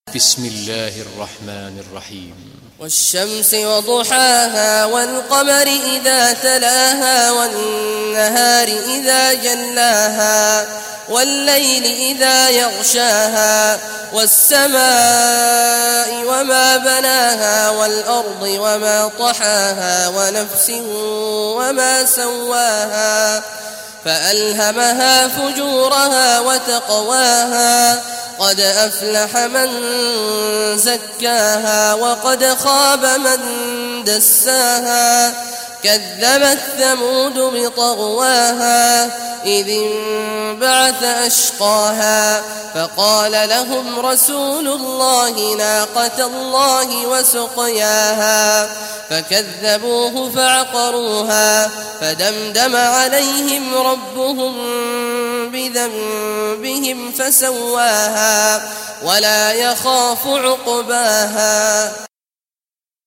Surah ash-Shams Recitation by Sheikh Awad Juhany
Surah ash-Shams, listen or play online mp3 tilawat / recitation in Arabic in the beautiful voice of Sheikh Abdullah Awad al Juhany.